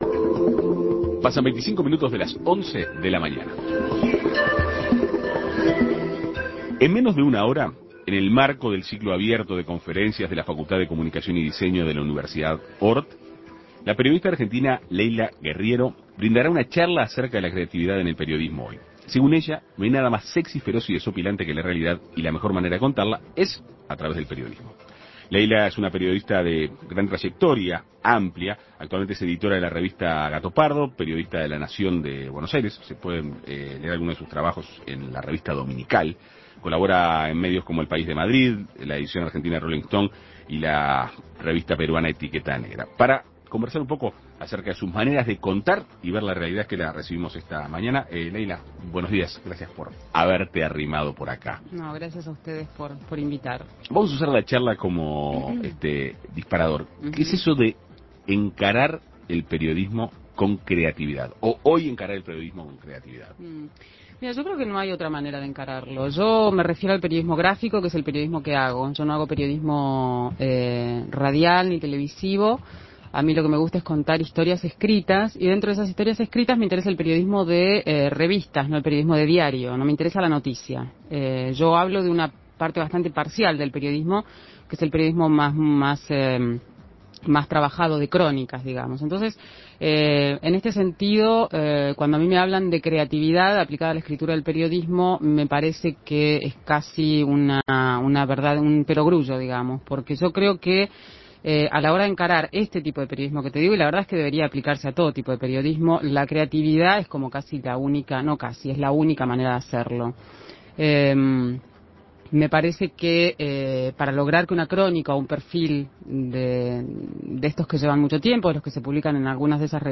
En Perspectiva Segunda mañana dialogó con la periodista argentina.